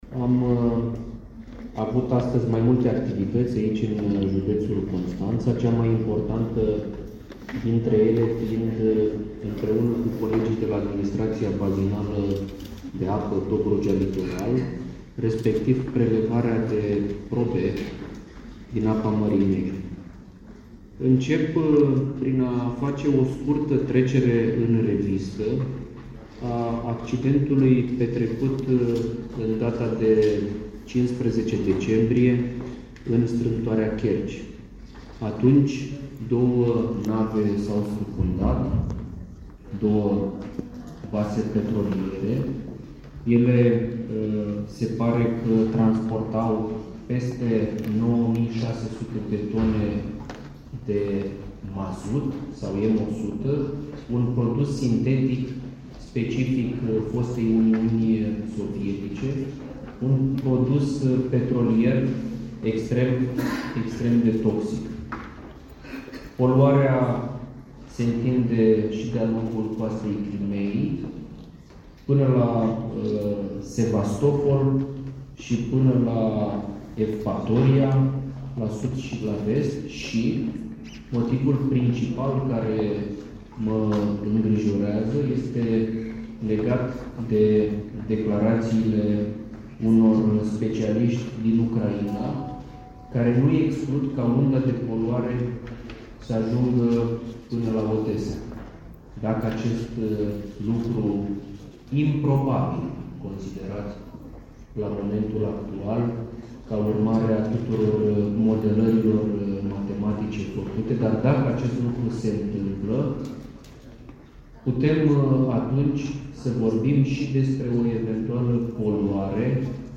Prezent la Constanța, ministrul Mircea Fechet a declarat că există îngrijorări exprimate de specialiștii ucraineni că unda de poluare ar putea ajunge la Odesa. În plus, măsurile de decontaminare sunt insuficiente, a declarat ministrul într-o conferință de presă: